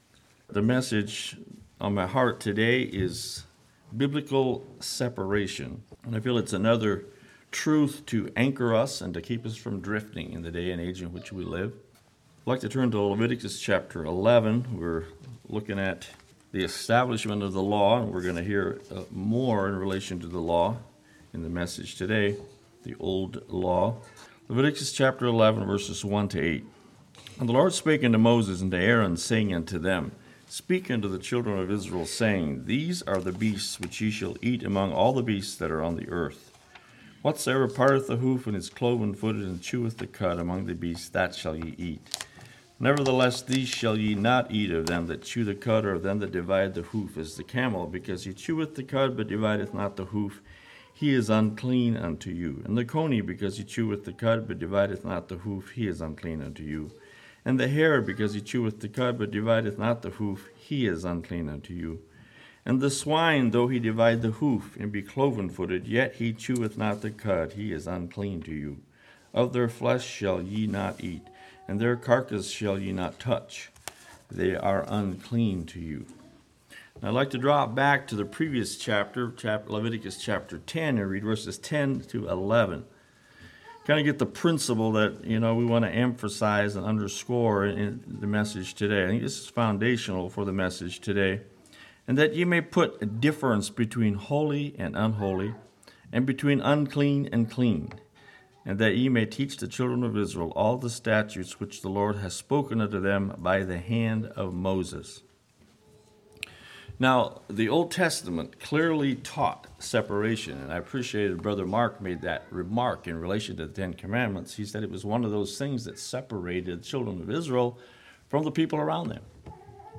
Sermon on Splitting the Hoof And chewing the Cud C